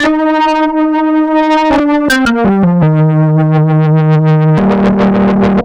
AC_OrganB_85-C.wav